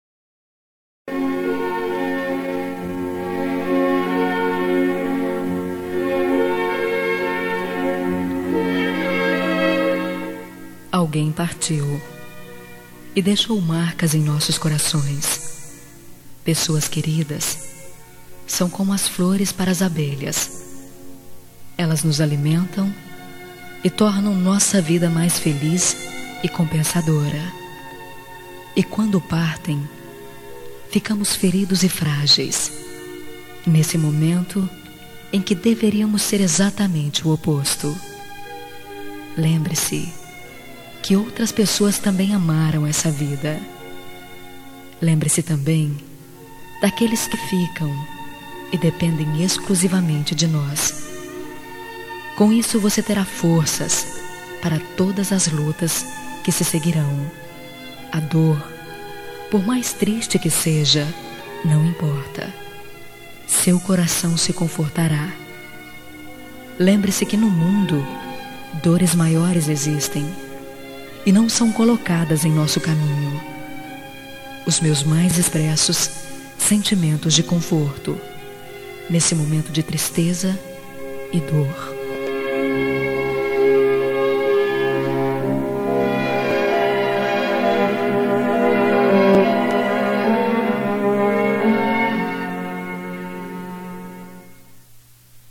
Telemensagem Pêsames – Voz Feminina – Cód: 5257